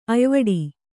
♪ ayvaḍi